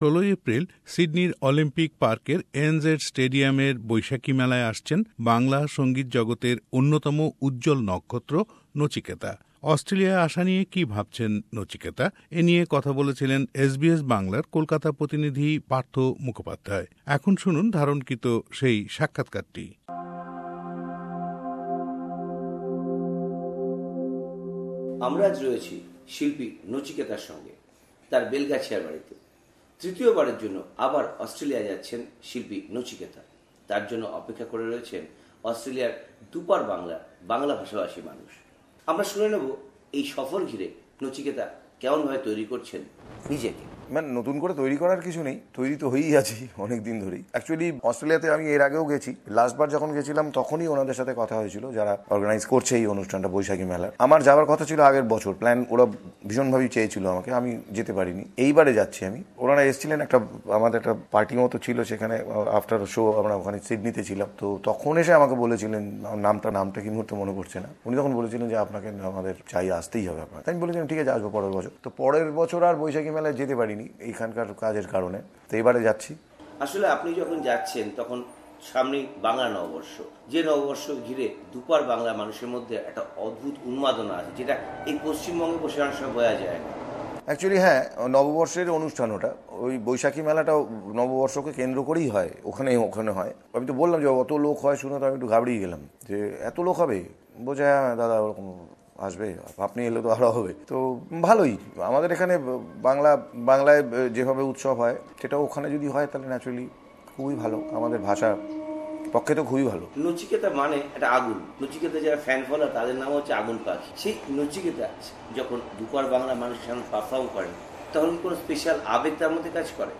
Interview with Nachiketa